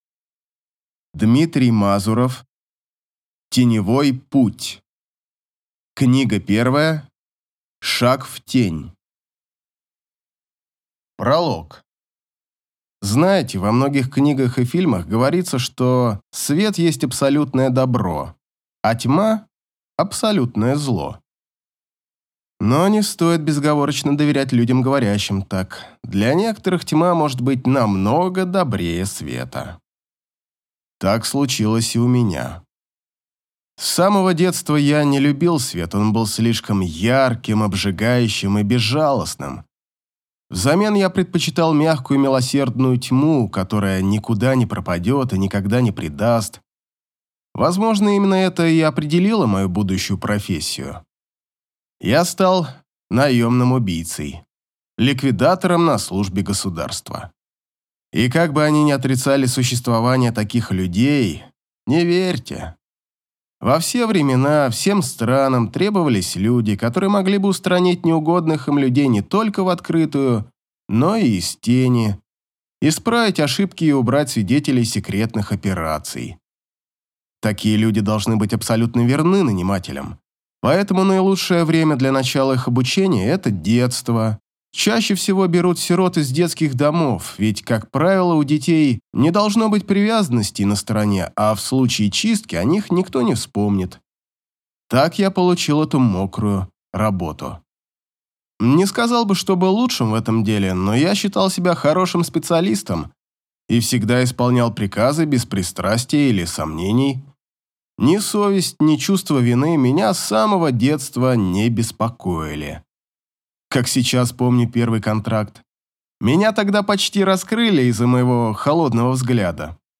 Аудиокнига Шаг в тень | Библиотека аудиокниг